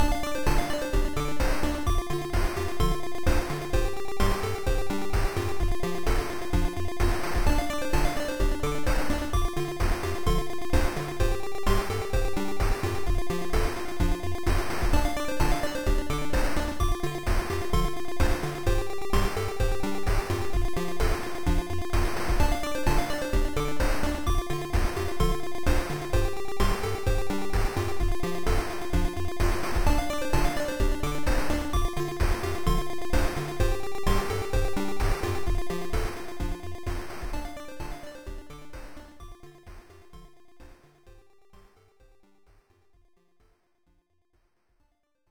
I'm trying to learn to make tracker music with Furnace, but it's not letting me save anything for some stupid reason.
It's wonky, but I think I've found a way around it.
I seem to be getting the hang of rhythms at least.